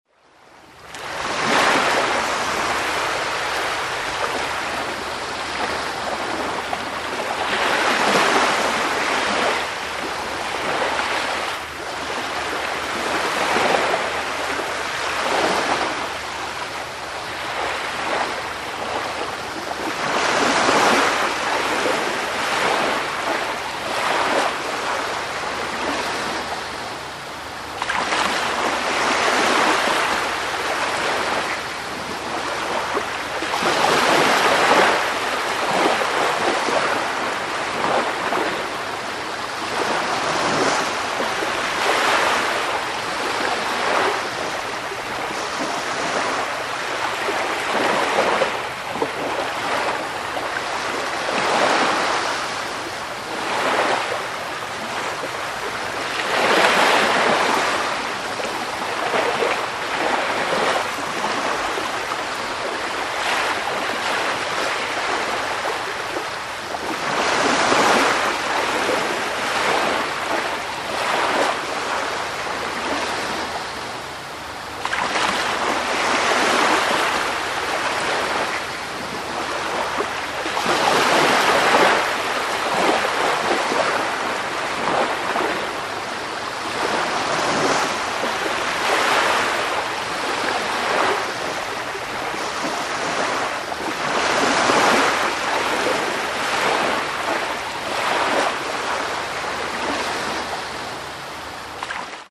Звуки дайвинга
Здесь вы найдете записи шумов акваланга, пузырей воздуха, скрипа снаряжения и других уникальных звуков морских глубин.